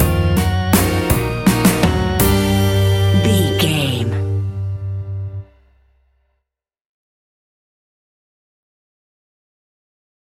Halloween Quirky Music Stinger.
Aeolian/Minor
scary
ominous
dark
eerie
piano
synthesiser
drums
electric organ
strings
horror music